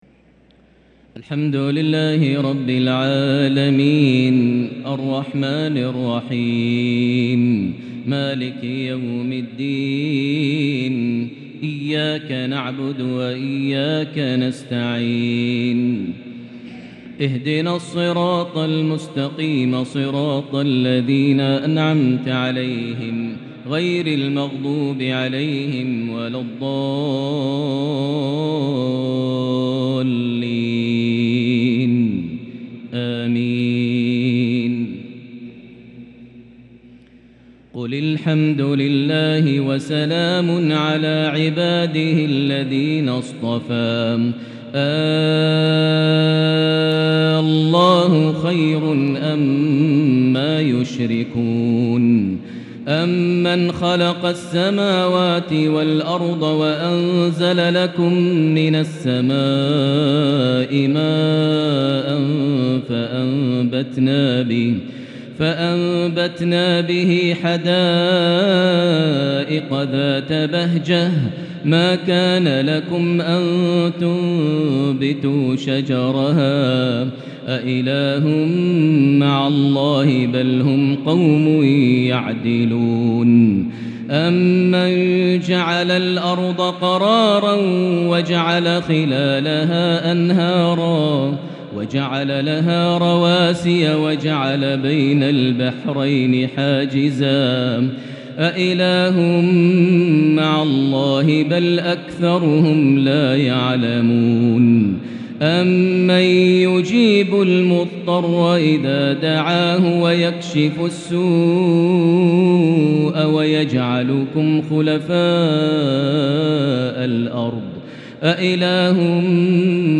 تهجد ليلة 23 رمضان 1443هـ| سورة النمل (59) سورة القصص (75) |Tahajjud 23st night Ramadan 1443H - Surah An-Naml 59 + Surah Al-Qasas 75 > تراويح الحرم المكي عام 1443 🕋 > التراويح - تلاوات الحرمين